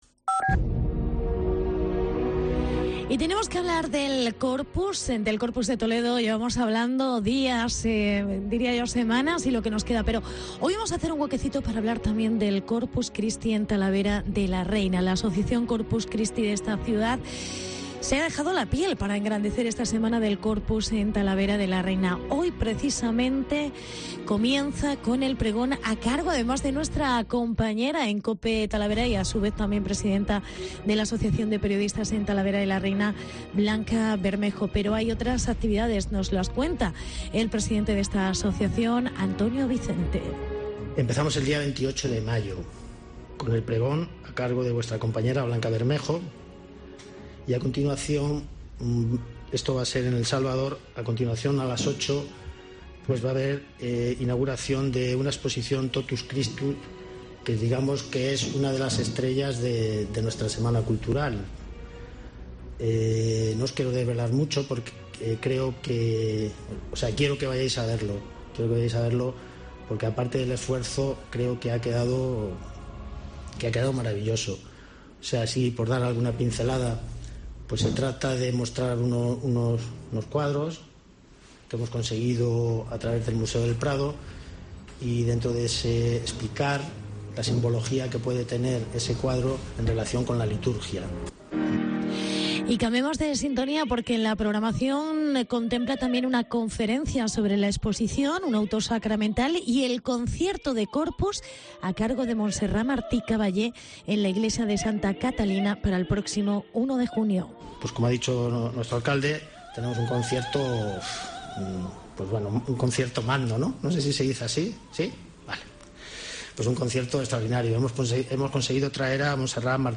Reportaje sobre el Corpus de Talavera